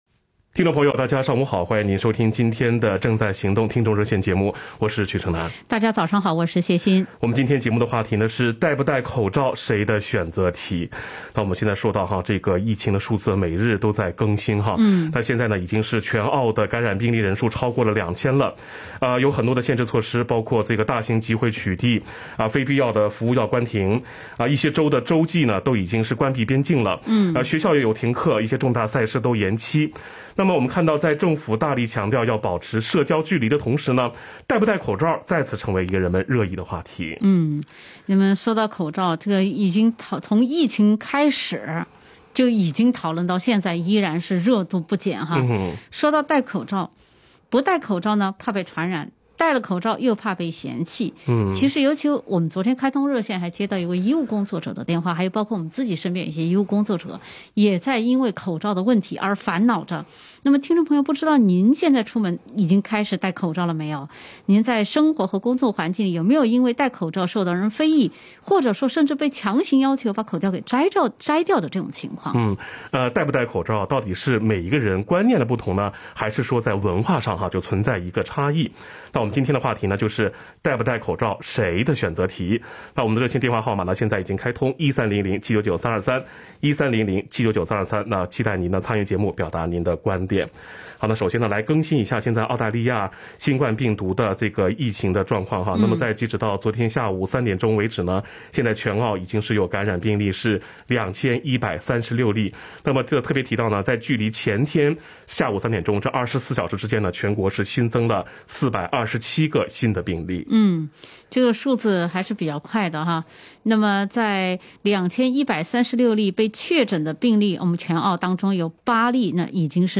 action_talkback_march_25_new.mp3